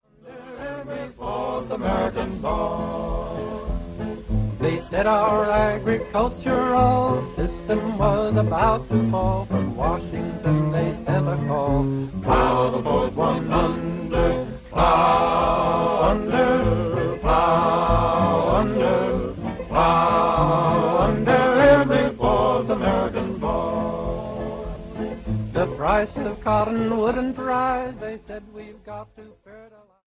lead vocal